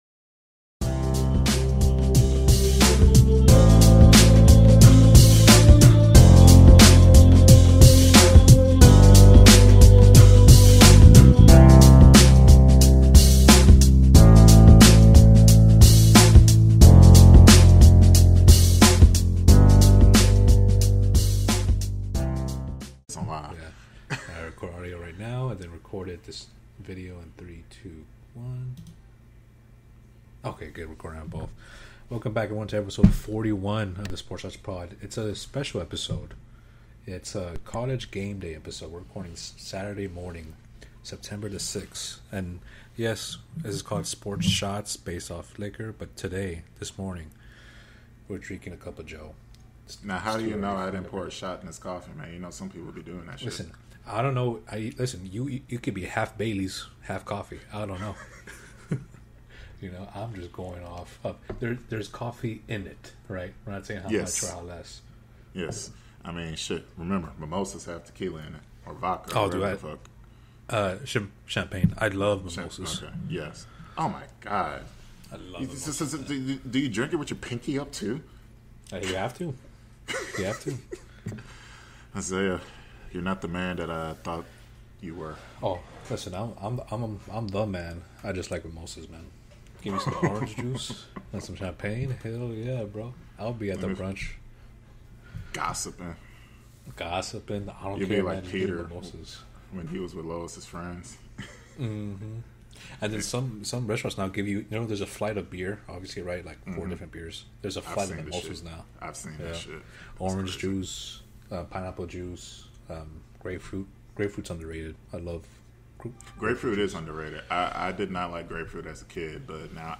Episode 41 is a Saturday morning special, our first-ever College GameDay recording session. With coffee cups in hand (and maybe a little something extra), we dove into NFL Week 1, college football headlines, Astros clubhouse drama, and one epic rant about streaming paywalls.
The show kicks off with some early-morning banter about brunch drinks, mimosas, and what might actually be inside the coffee cup.